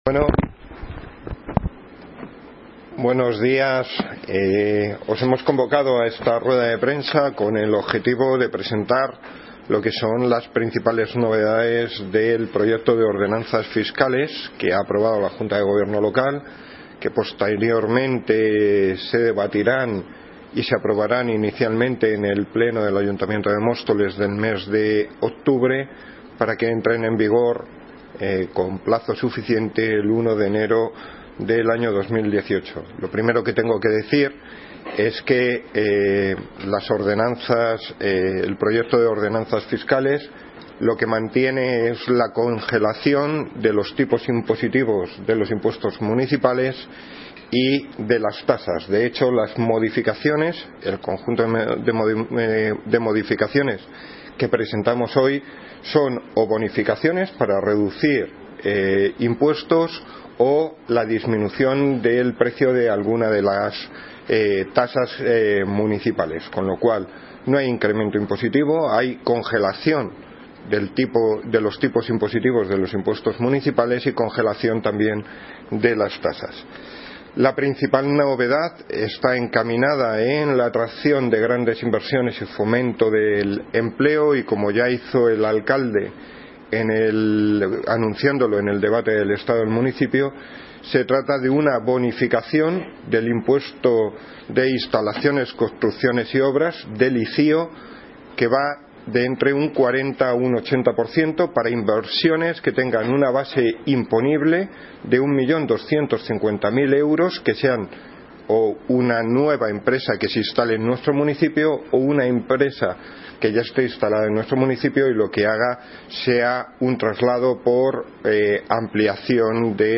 Noticia Fecha de publicación: 16 de octubre de 2017 El concejal de Hacienda y Patrimonio, Javier Gómez, ha explicado el proyecto de Ordenanzas Fiscales,...
Audio - Javier Gómez (Concejal de Hacienda y Patrimonio) Sobre Ordenanzas fiscales